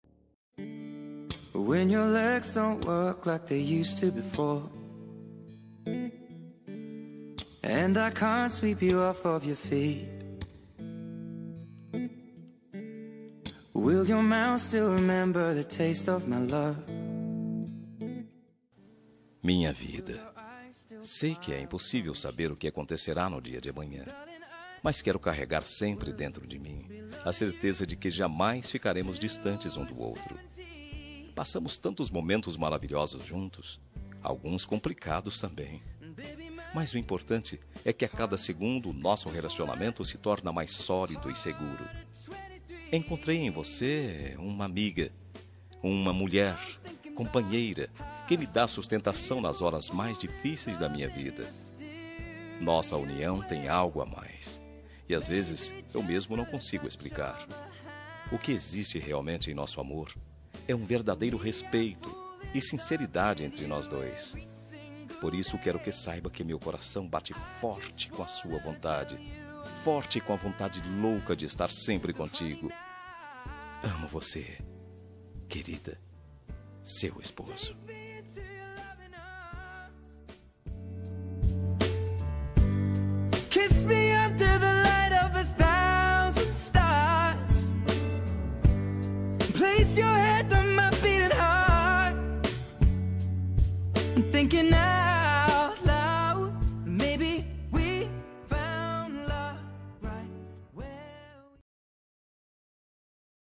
Telemensagem Romântica para Esposa – Voz Masculina – Cód: 5201